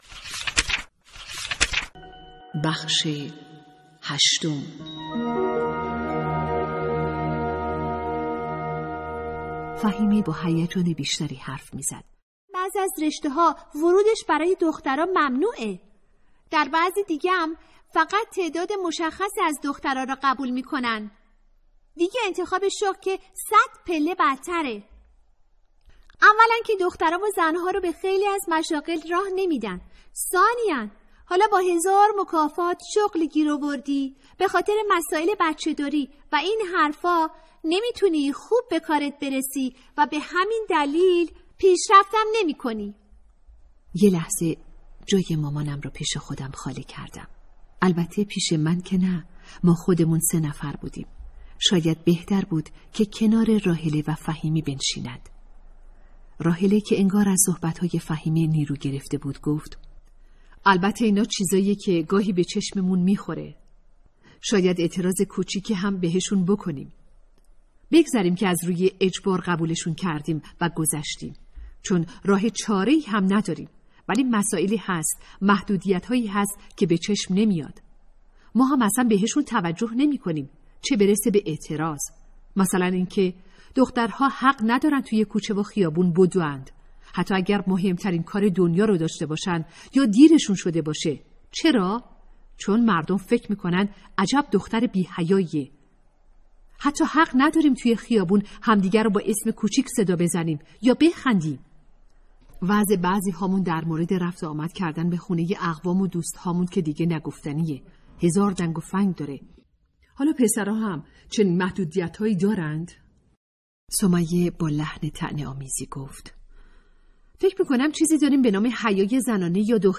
کتاب صوتی | دختران آفتاب (08)